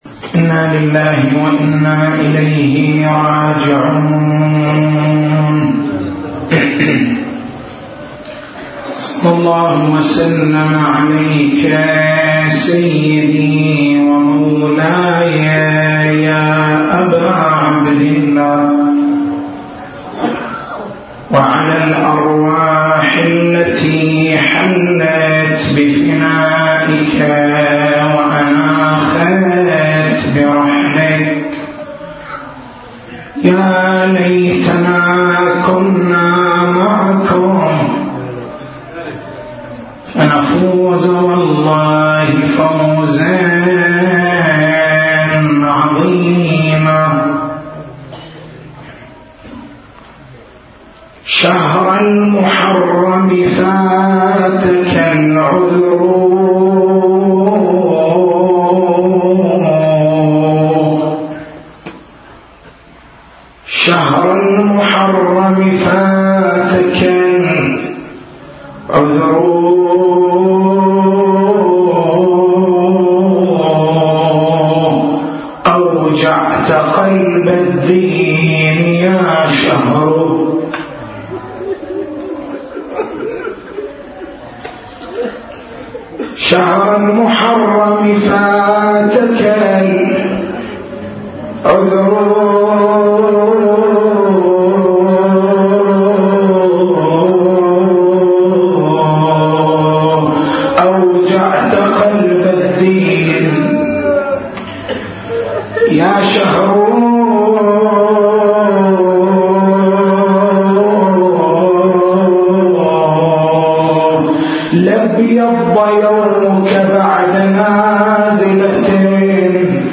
تاريخ المحاضرة: 03/01/1430 نقاط البحث: بيان معنى كون العصمة من العقائد الضرورية ما هو معنى الضروري؟